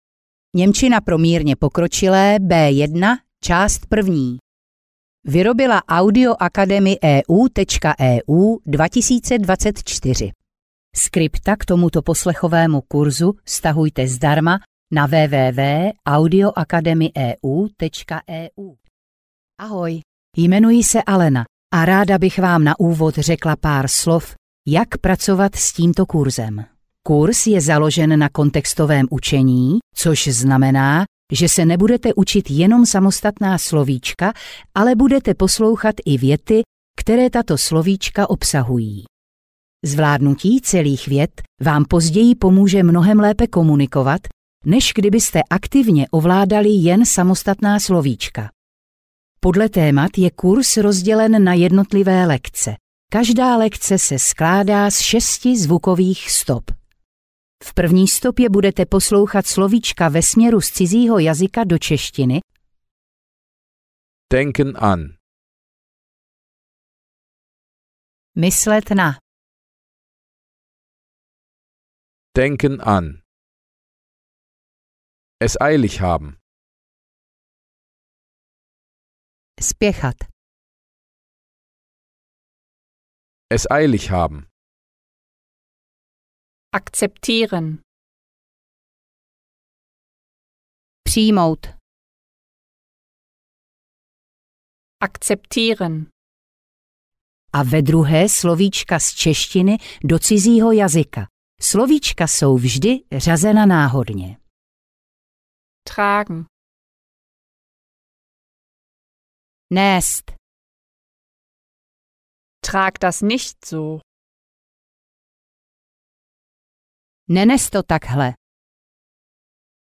Audio knihaNěmčina pro mírně pokročilé B1 – část 1
Ukázka z knihy